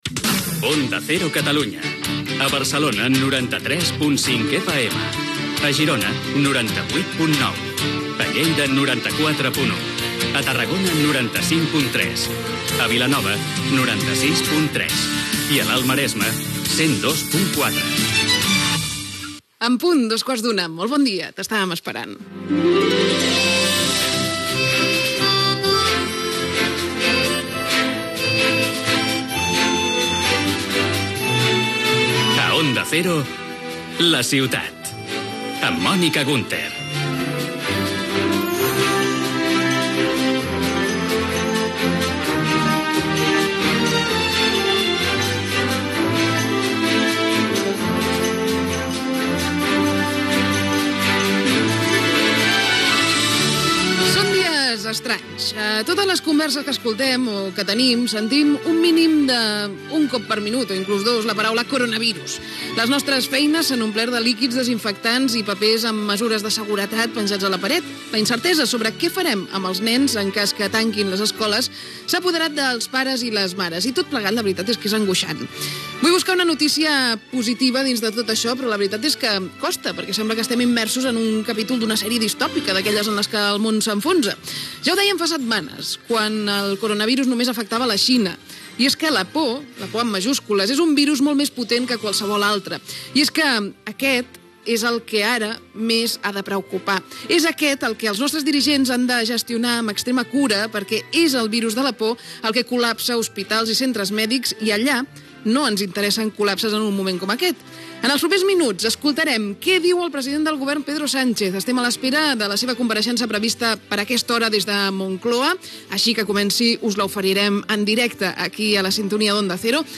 Indicatiu de la cadena a Catalunya, amb les emissores i freqüències.
Informatiu
FM